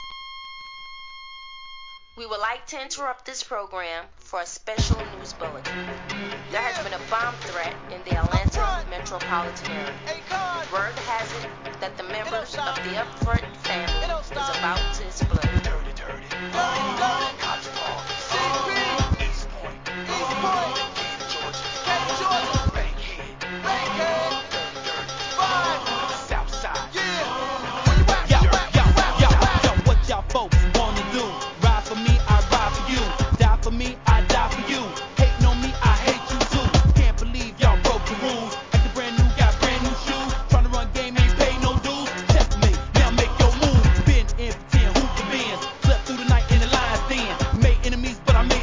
HIP HOP/R&B
変則的なビートに「アーハ−!アーハー!」の叫びが癖になる一曲！